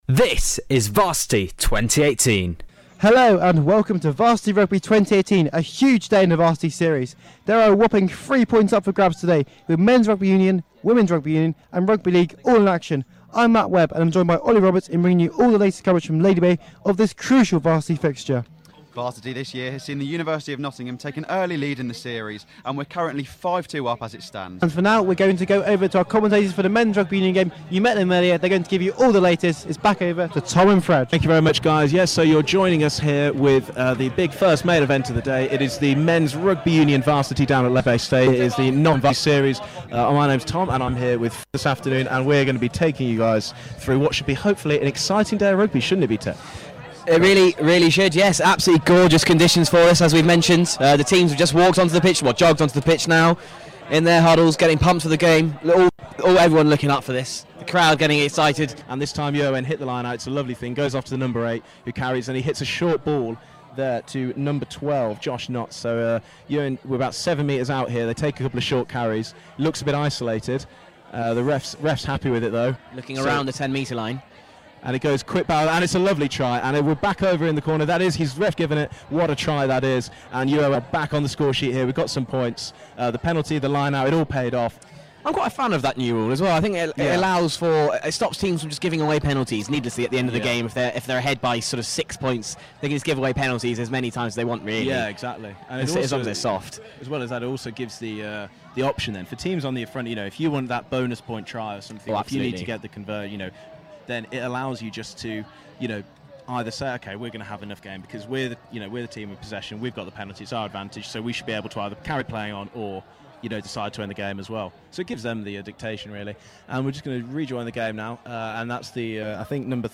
Catch our highlights of Varsity Rugby Union 2018 from a scorching day at Lady Bay, as the University of Nottingham took on Nottingham Trent University. With UoN leading the series, could they extend their lead or would NTU edge back into the series?